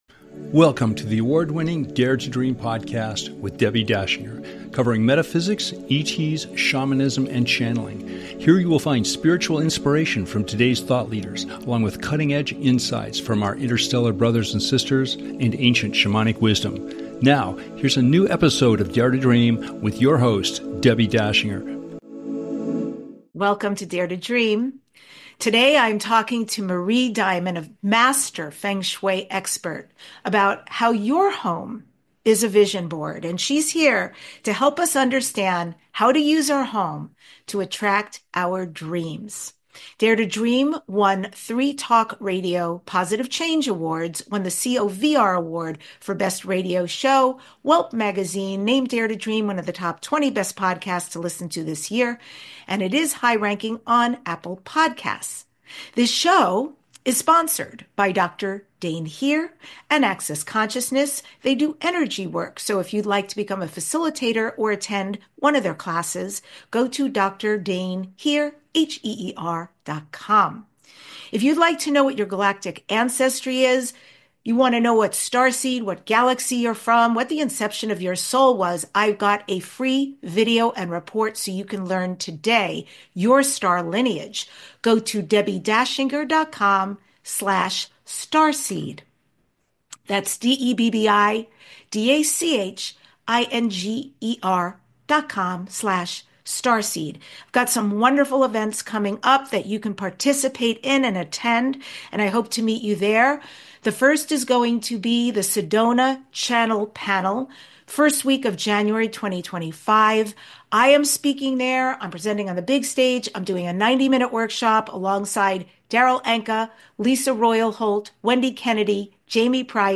Guest, Marie Diamond a world-renowned Feng Shui Master and teacher of the Law of Attraction